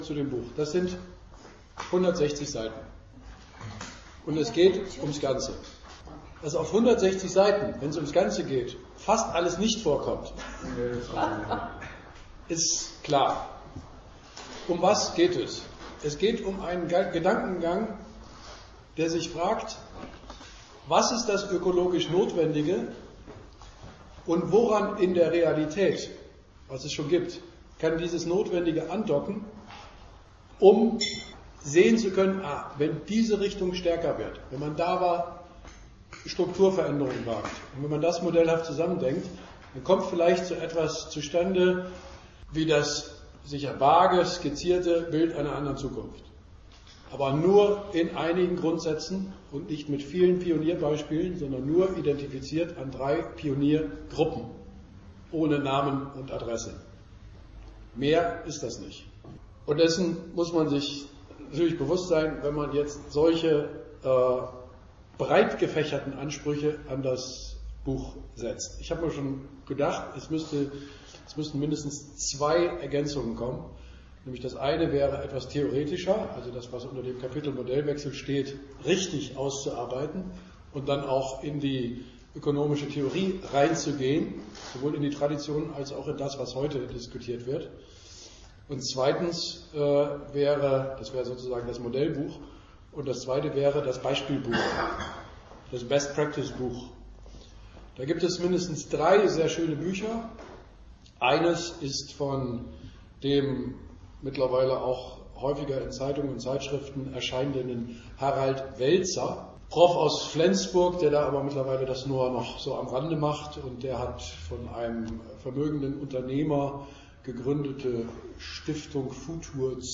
Im Podcast stellt er sich den Fragen und Anmerkungen, die das Publikum nach der Lesung aus seinem Buch an ihn hatte.